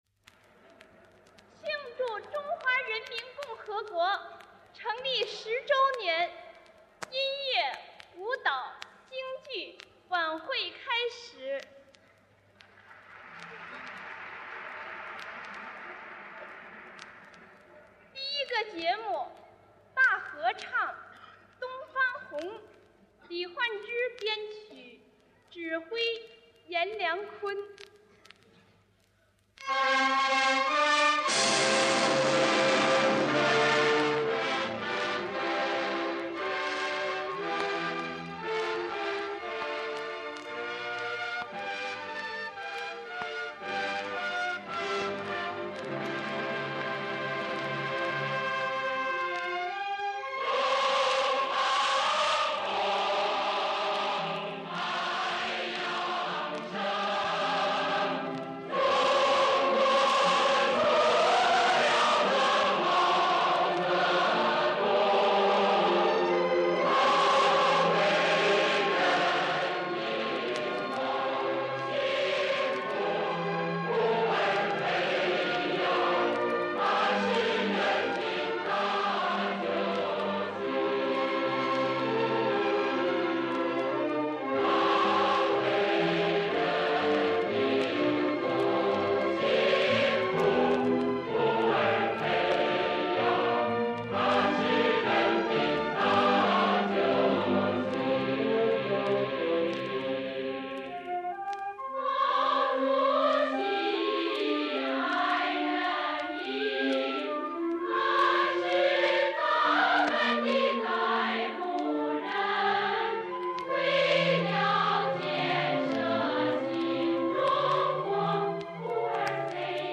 [26/12/2021]【纪念毛泽东诞辰128周年】国庆十周年文艺晚会演唱大合唱《东方红》（演出实况录音）
编曲：李焕之 指挥：严良堃 演唱：庆祝国庆十周年文艺晚会合唱队（1959年）